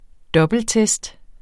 Udtale [ ˈdʌbəlˌtεsd ]